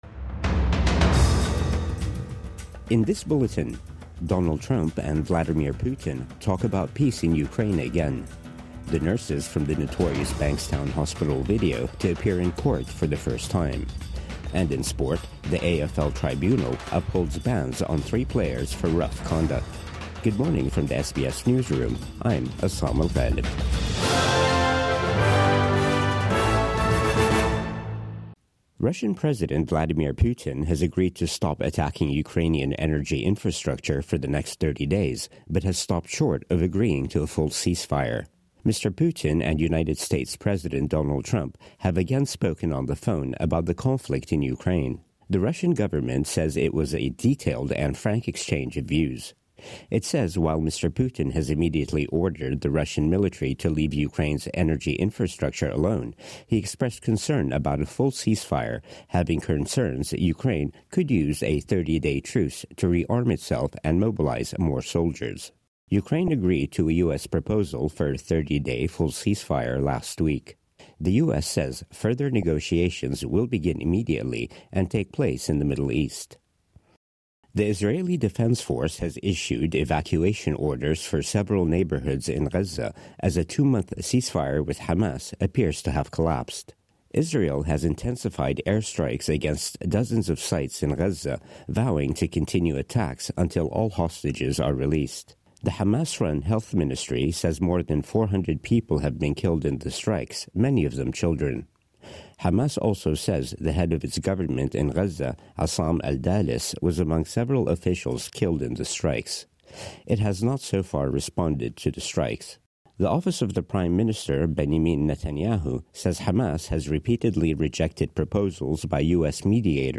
Putin agrees no attacks on Ukrainian energy - but no full ceasefire | Morning News Bulletin 19 March 2025